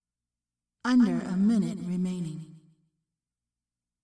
视频游戏中的女声 " 关门
标签： 讲话 谈话 发誓字 声音 女孩 性感 疯狂 美国 性别 声乐 近距离的门 幸福 女人 搞笑
声道立体声